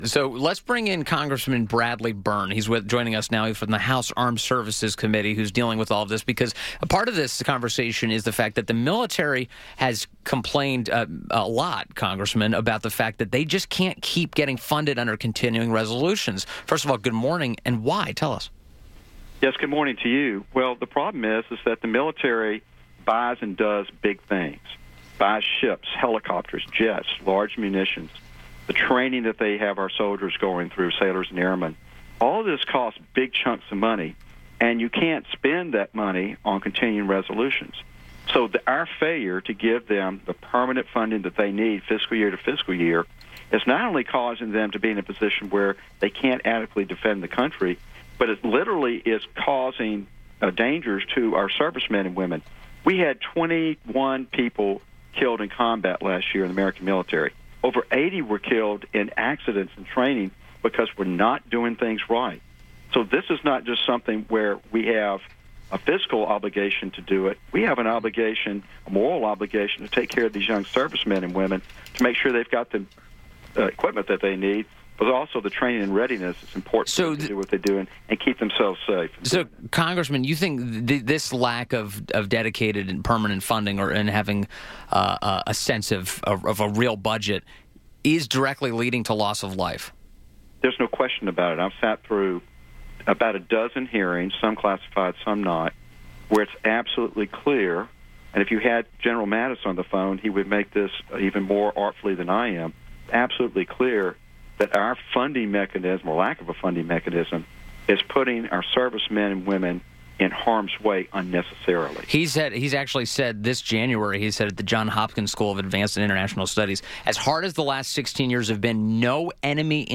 WMAL Interview - REP. BRADLEY BYRNE - 02.06.18